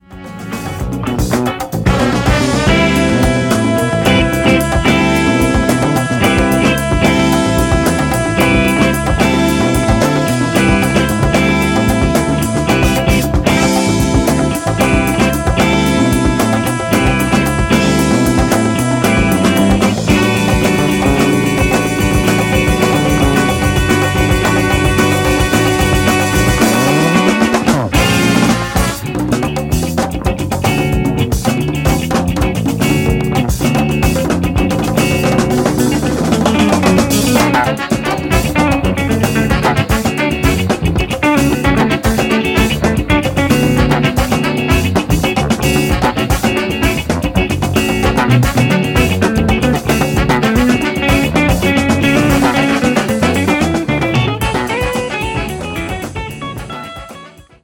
Two killer 70's lost funk sides